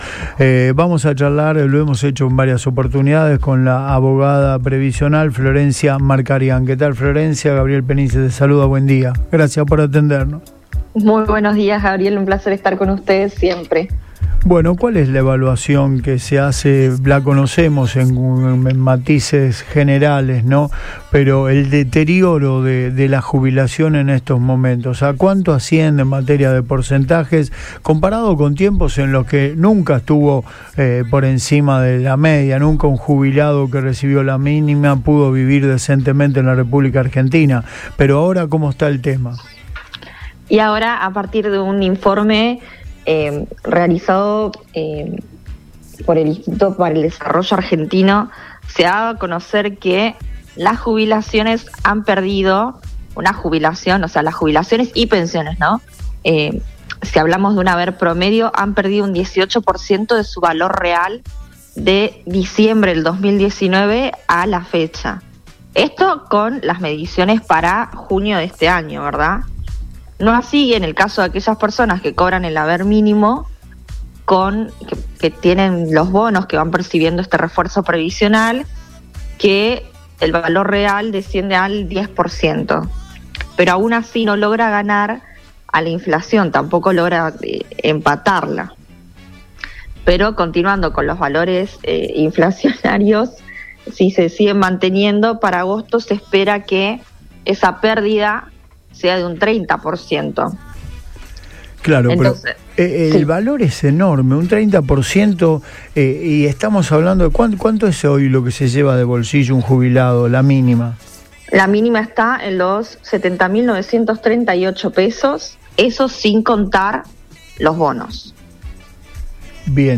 EN RADIO BOING